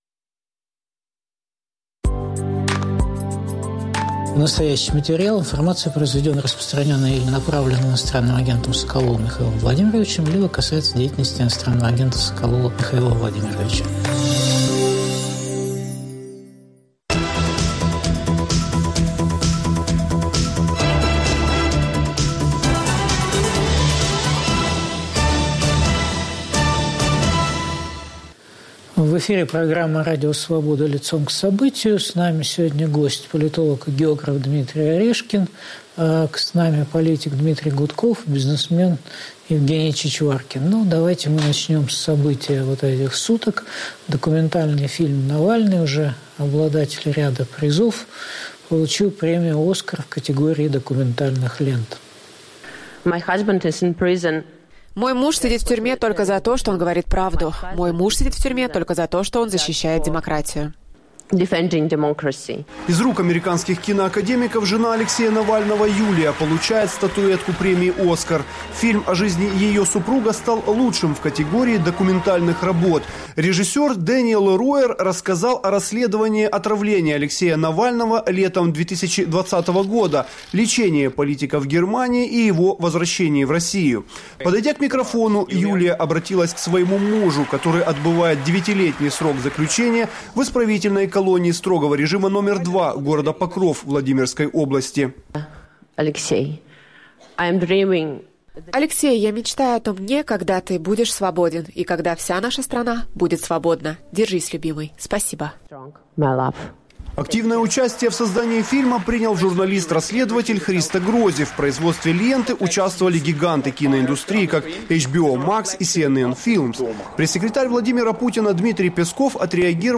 Есть ли эффект Оскара? В эфире Дмитрий Гудков, Дмитрий Орешкин, Евгений Чичваркин.